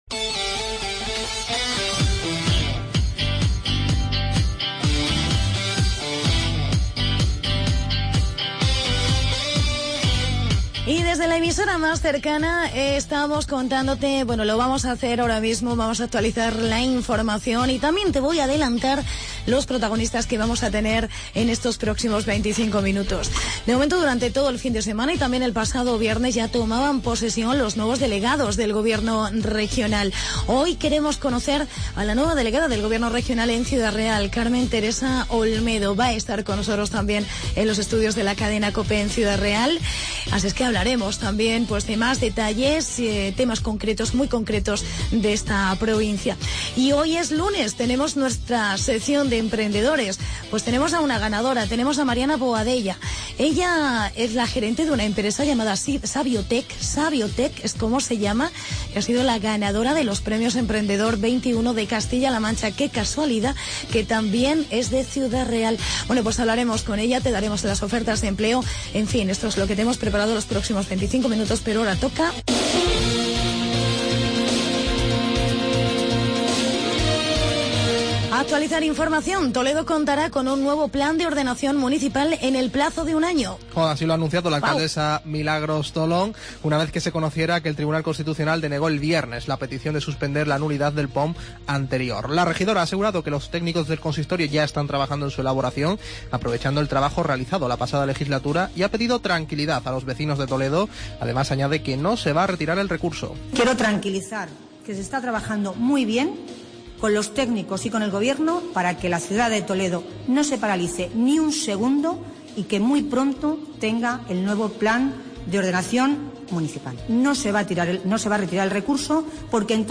Entrevista con la Delegada del Gobierno en Ciudad Real